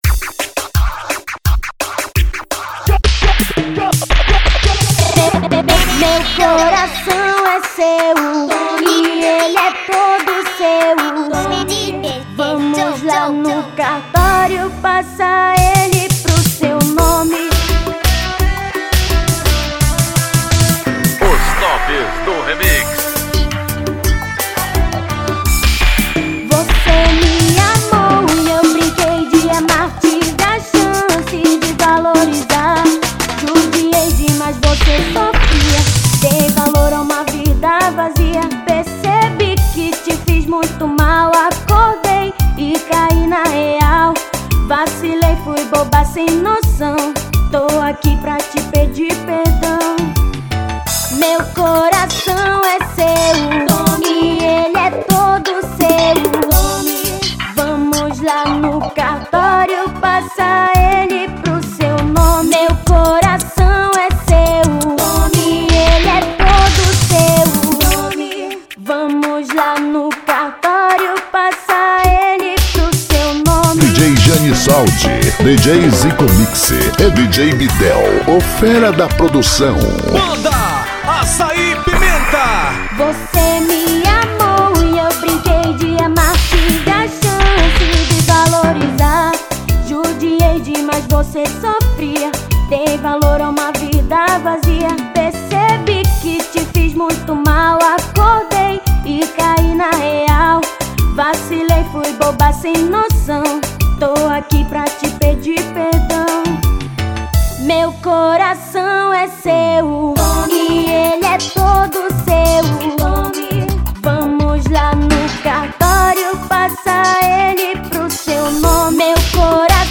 melody novo 2015 agosto.